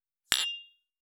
263,ショットグラス乾杯,乾杯,アルコール,バー,お洒落,モダン,カクテルグラス,ショットグラス,おちょこ,テキーラ,シャンパングラス,カチン,チン,カン,ゴクゴク,プハー,シュワシュワ,コポコポ,ドボドボ,
コップ効果音厨房/台所/レストラン/kitchen食器